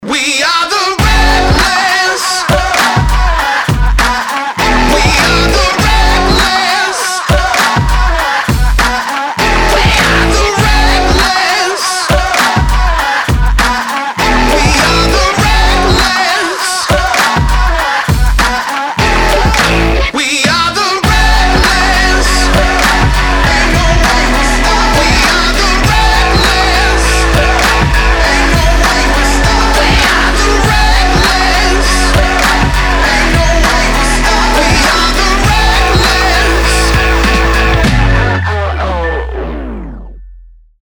мужской вокал
заводные
электрогитара
alternative
indie rock
бодрые
Бодрый рингтон